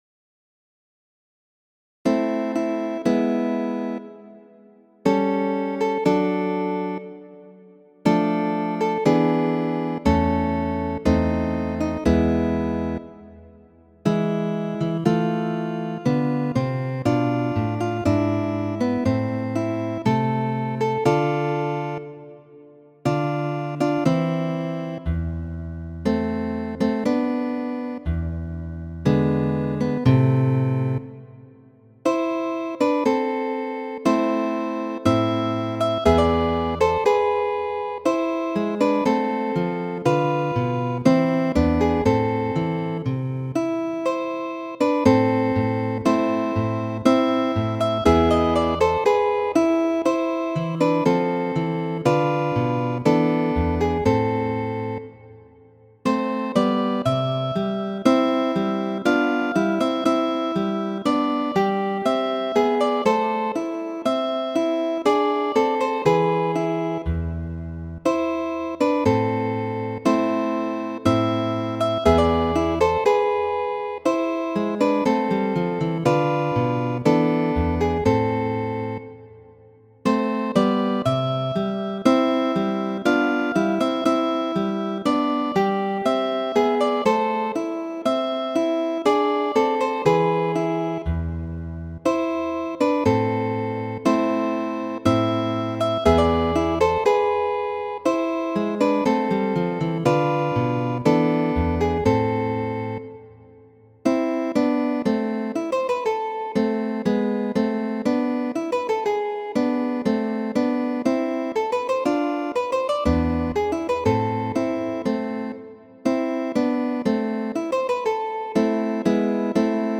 Muziko:
Variaĵoj pri franca temo Que ne suis-je de la fougère, komponita de Fernando Sor en 1827.